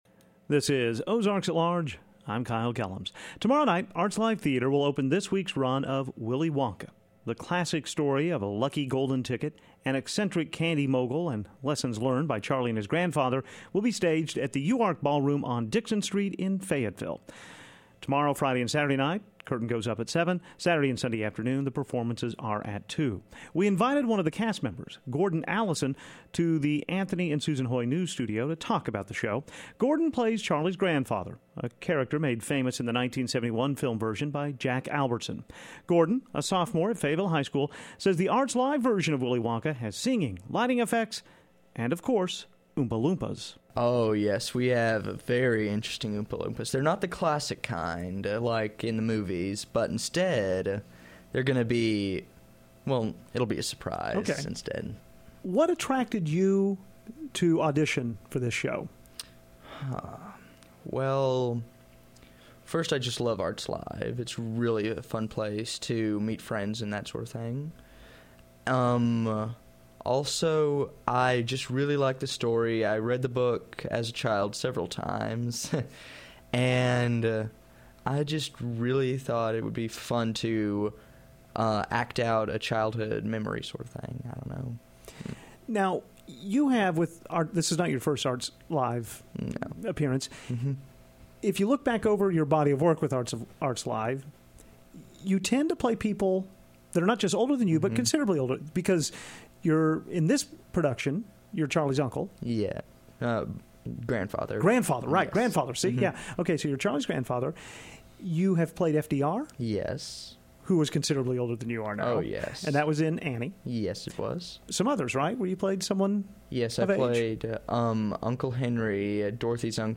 We talk with one of the cast members of the Arts Live Theatre production of Willy Wonka. The show opens tomorrow night.